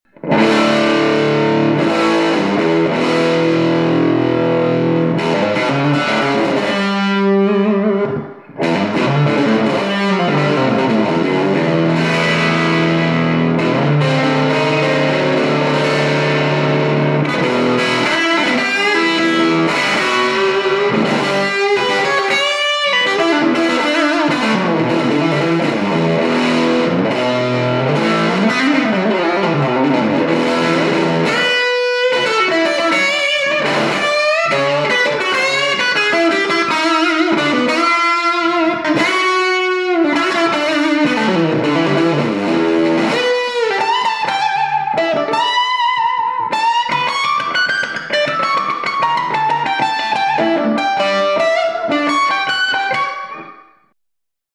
Room Les Paul High Gain 2   .55